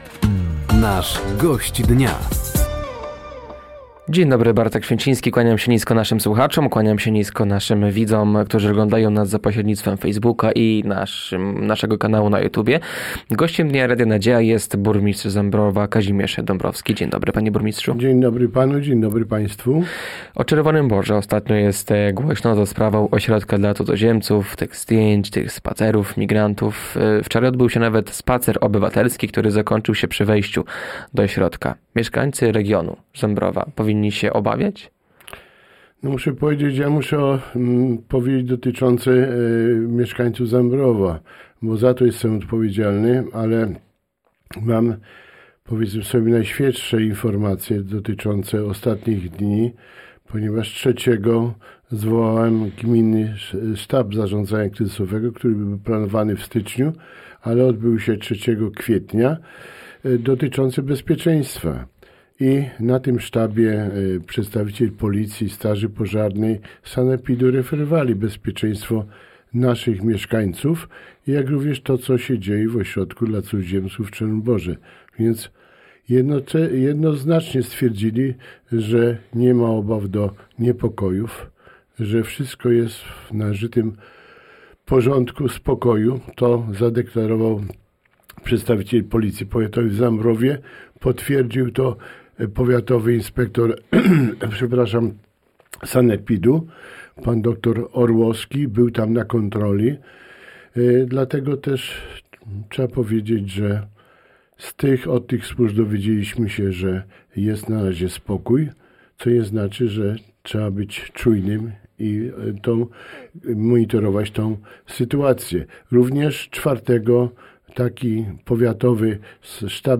Gościem Dnia Radia Nadzieja był dziś burmistrz Zambrowa Kazimierz Dąbrowski. Tematem rozmowy była ośrodek dla cudzoziemców w Czerwonym Borze, realizowanie przez samorząd ustawy o obronie cywilnej oraz prowadzone inwestycje w mieście.